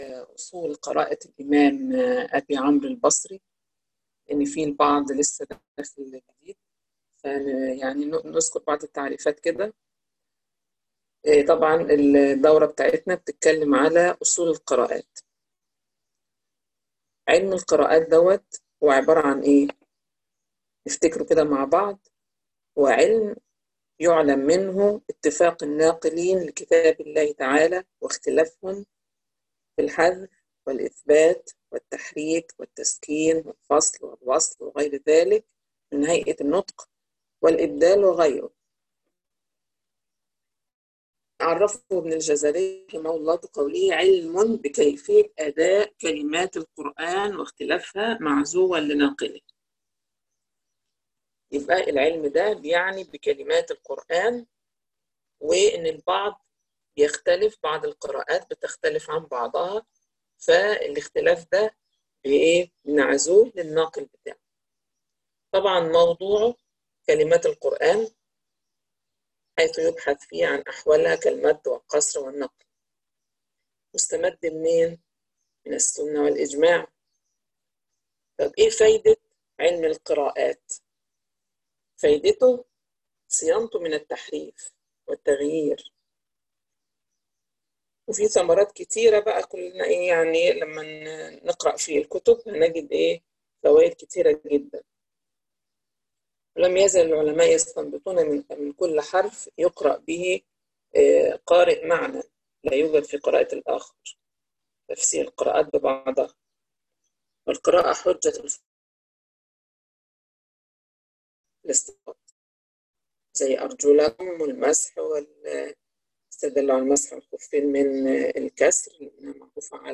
الدرس_2_الاستعاذة_والبسملة_أم_القرآن_ميم_الجمع_شرح_أصول_قراءة_الإمام يعقوب